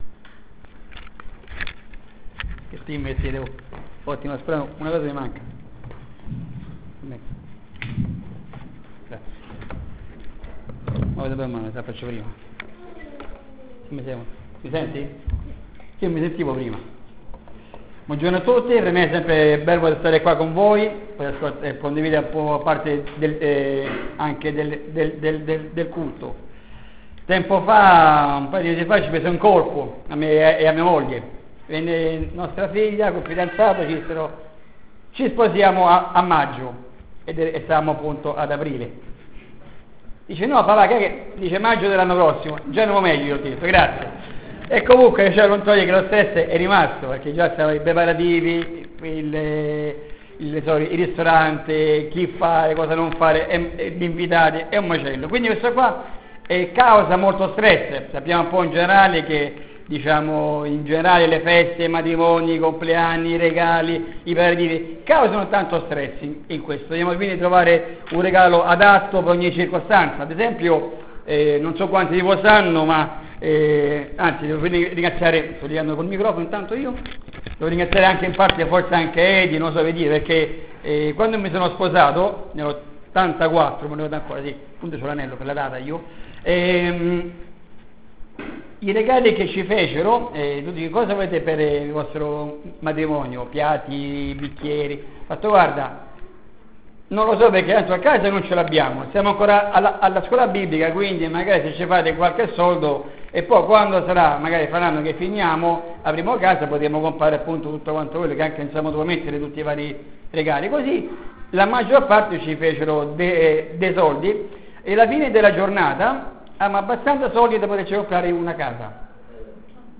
Predicazione